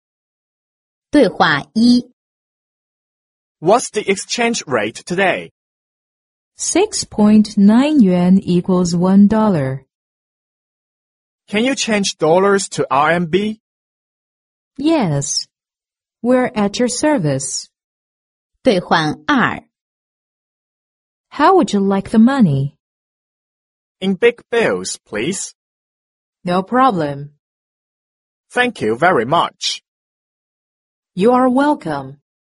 外贸服装口语 第78期:换汇之情景对话 听力文件下载—在线英语听力室